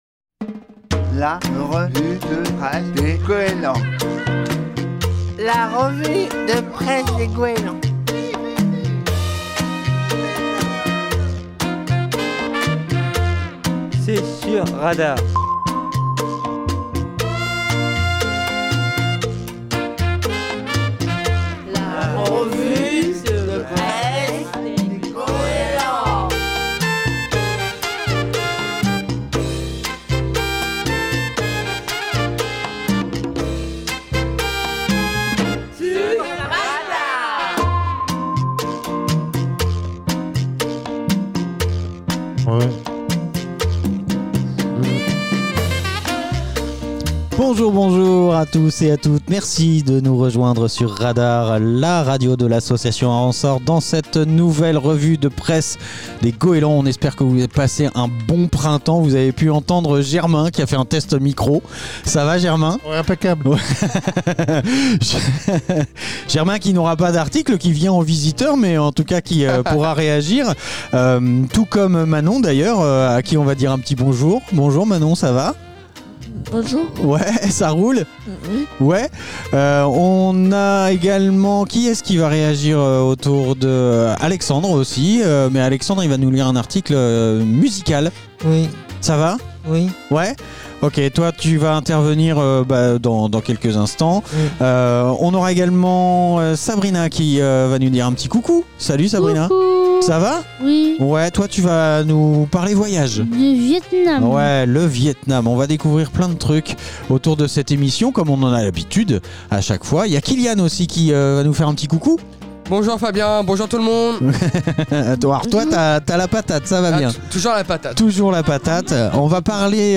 Les usagers du foyer d'activités des Goélands de Fécamp font leur revue de presse tous les 2ème mardis de chaque mois sur Radar